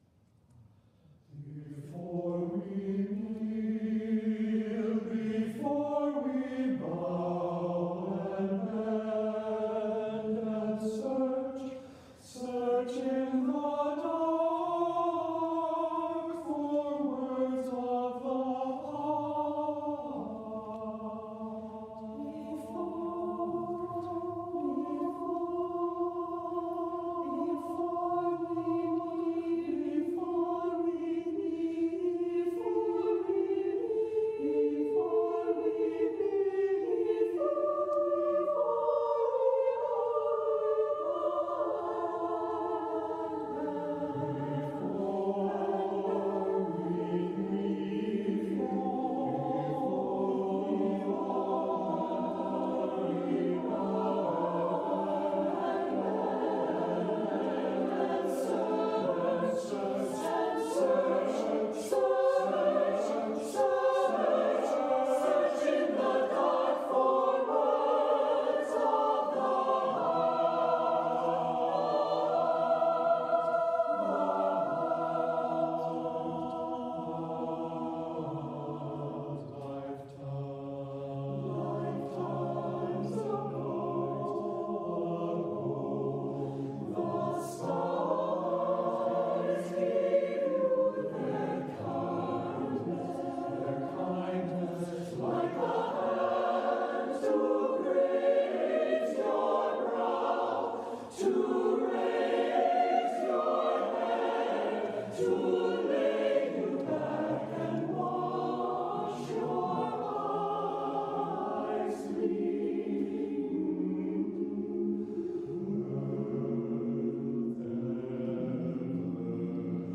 SATB a cappella chorus